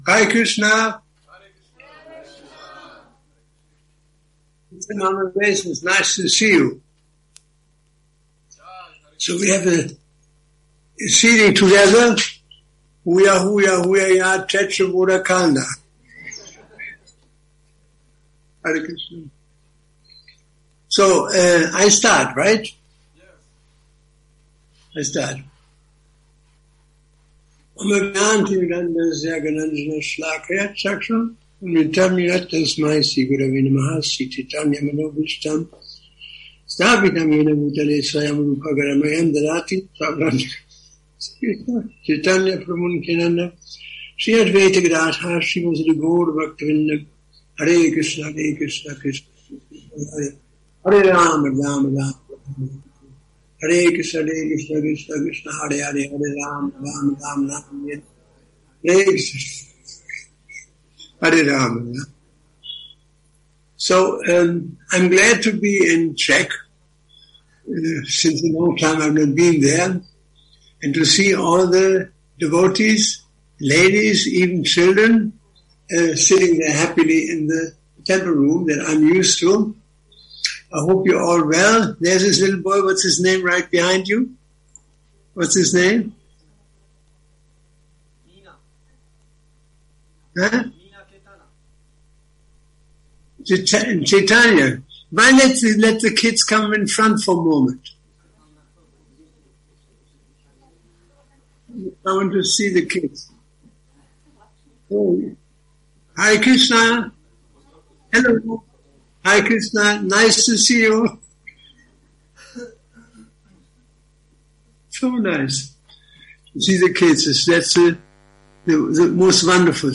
Přednáška SB-9.21.13 – Šrí Šrí Nitái Navadvípačandra mandir